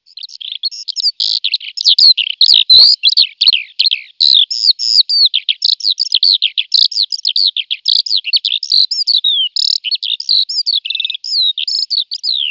L'allodola, l'ugola d'oro dei campi
allodola c.wav